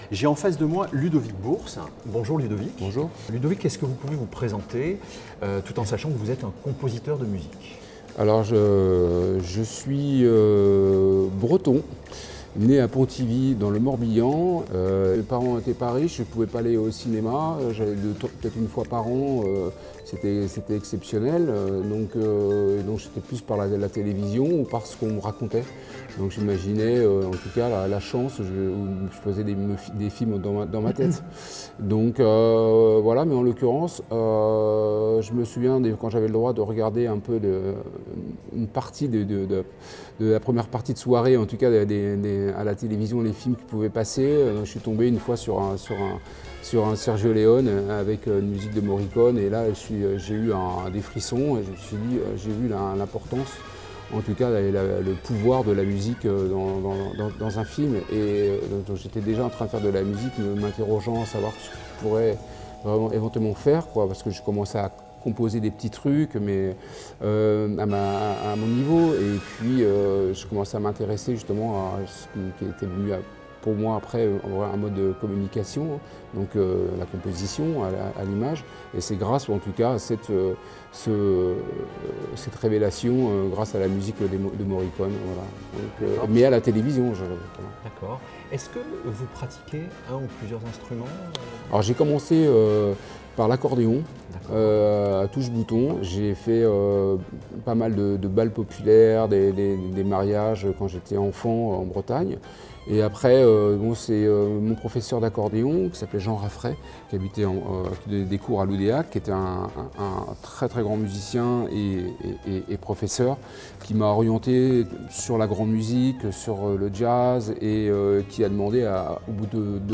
Des débuts modestes en Bretagne